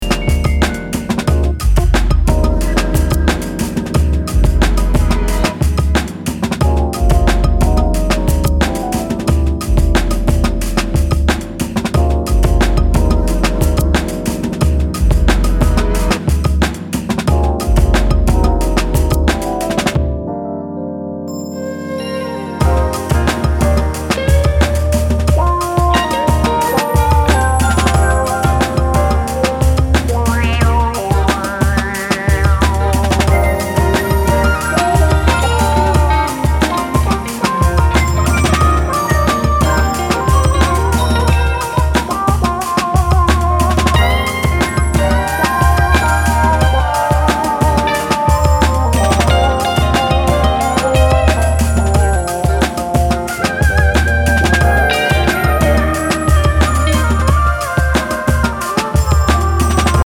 ブロークンビーツ的なドラミングにファンキーなベース・ライン
開放的な空気感を纏った爽やかで抜けのあるジャズファンク・ハウス〜ダウンテンポを繰り広げています。